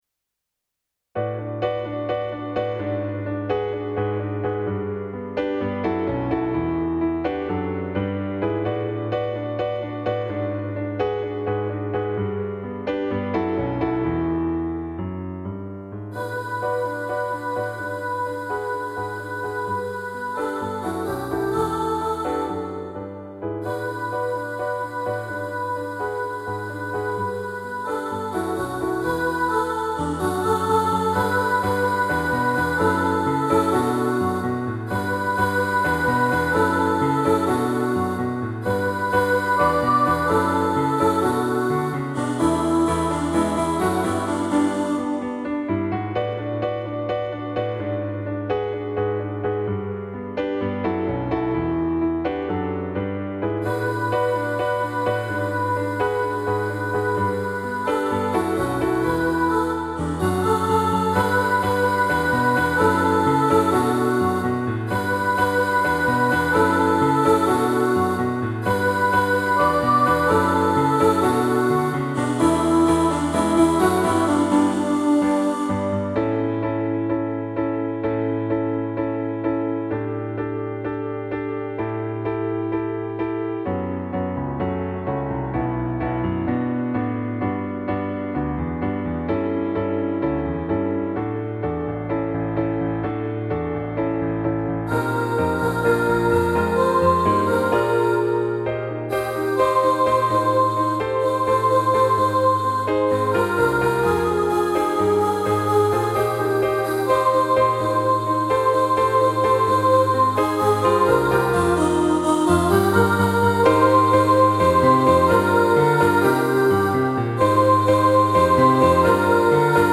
Aint-No-Mountain-Soprano.mp3